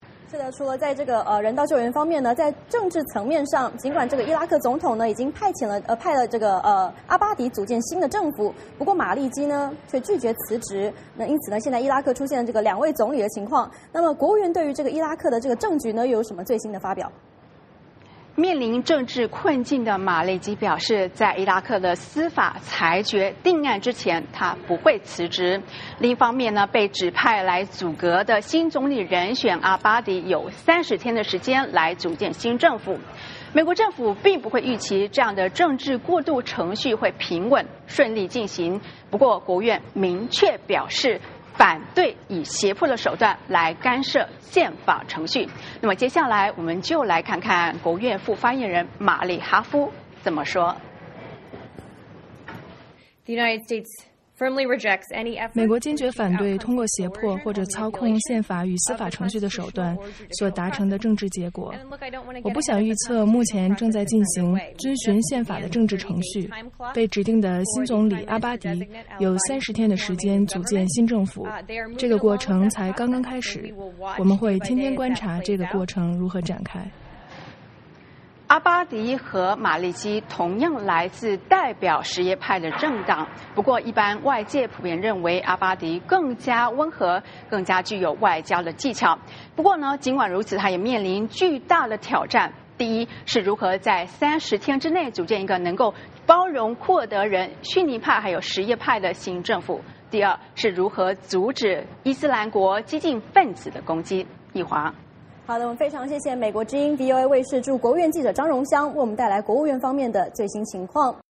VOA连线：国务院：反对胁迫手段干涉伊拉克宪法程序